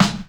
• Nineties Strong Bottom End Hip-Hop Acoustic Snare Sound G Key 402.wav
Royality free acoustic snare sound tuned to the G note. Loudest frequency: 1431Hz
nineties-strong-bottom-end-hip-hop-acoustic-snare-sound-g-key-402-bMj.wav